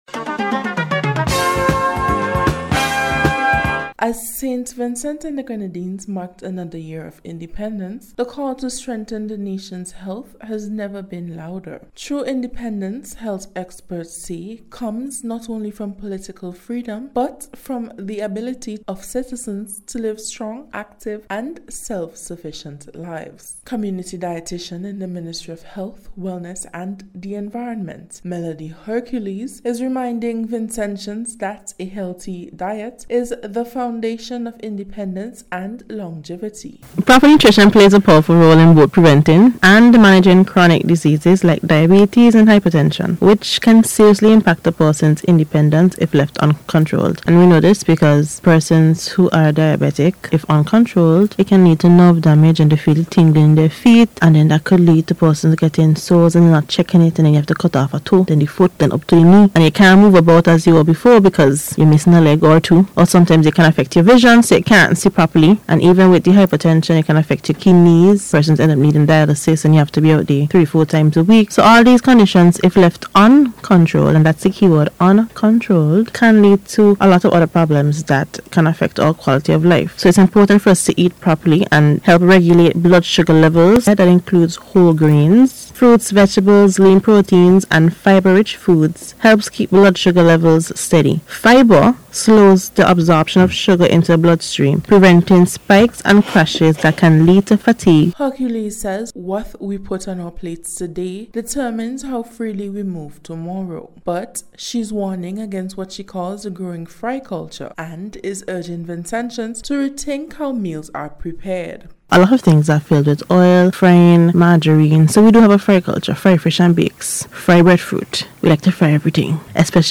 HEALTH-AND-INDEPENDENCE-REPORT.mp3